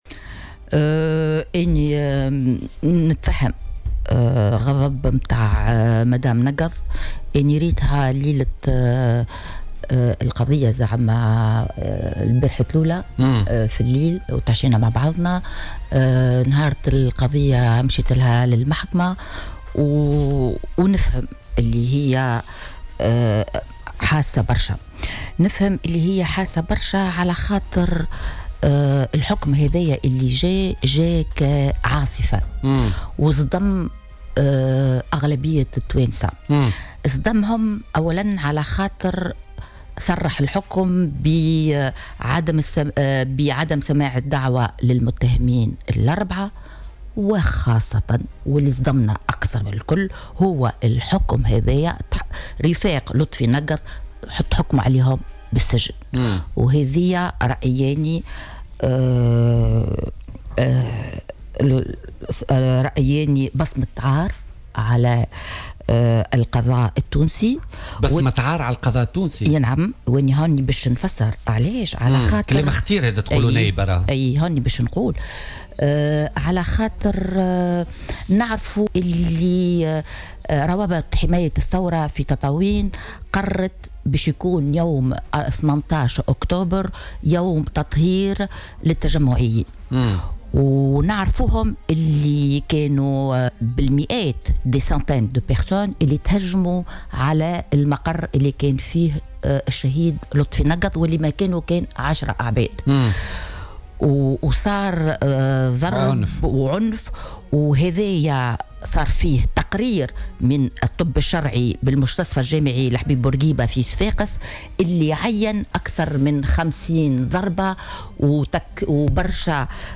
في حوار مع الجوهرة أف أم